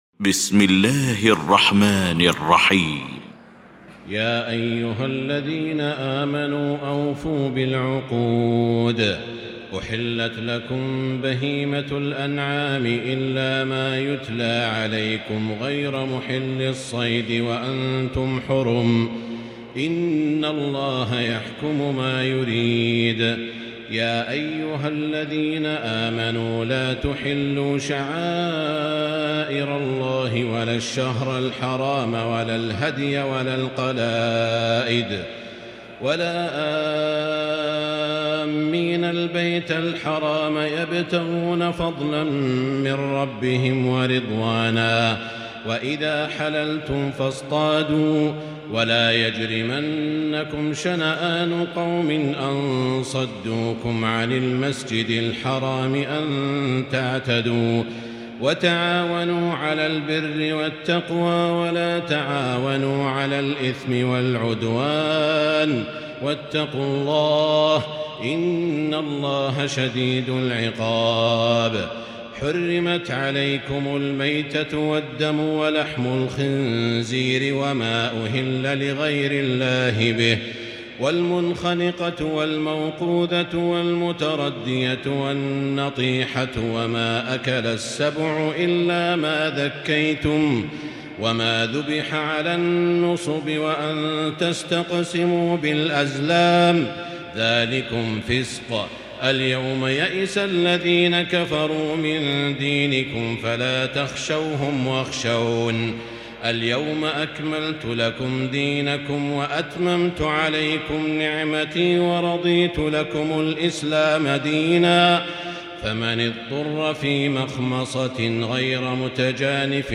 المكان: المسجد الحرام الشيخ: سعود الشريم سعود الشريم فضيلة الشيخ عبدالله الجهني فضيلة الشيخ ياسر الدوسري المائدة